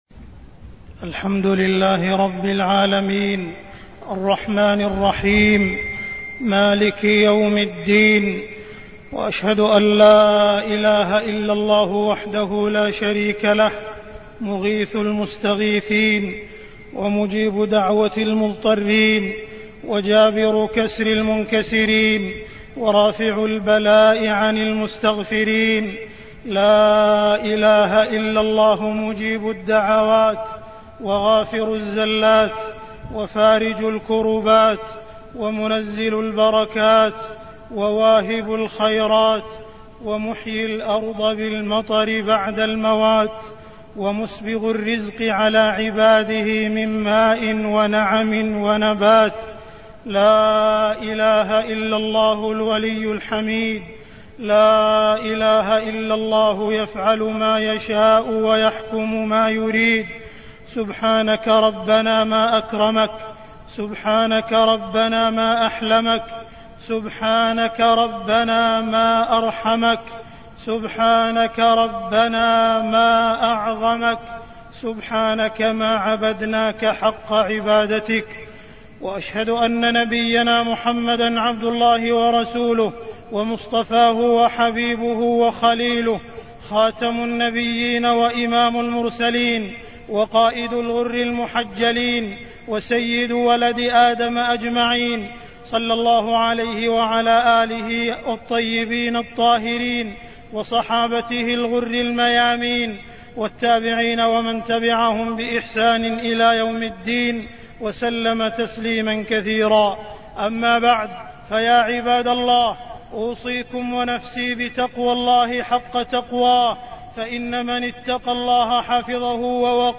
تاريخ النشر ٢١ شعبان ١٤٢٠ هـ المكان: المسجد الحرام الشيخ: معالي الشيخ أ.د. عبدالرحمن بن عبدالعزيز السديس معالي الشيخ أ.د. عبدالرحمن بن عبدالعزيز السديس دلائل وحدانية الله